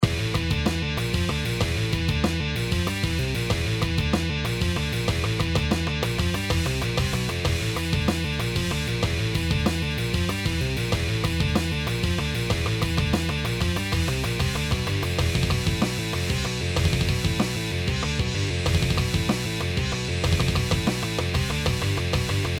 5/8 then 3/4 , 17/16 and 15/16 (!!!!!)